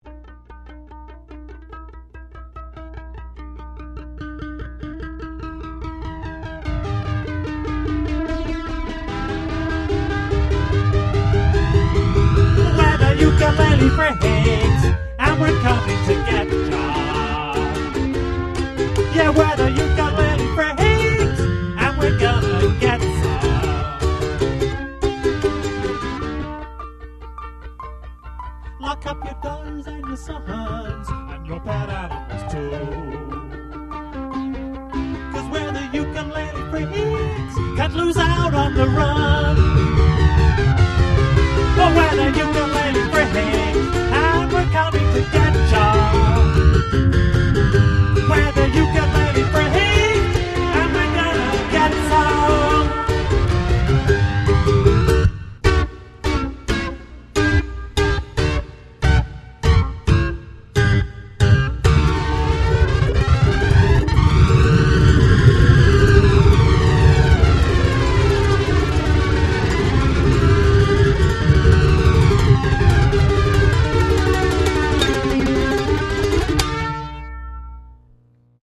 De l'alternatif sur l'instrument traditionnel hawaïen.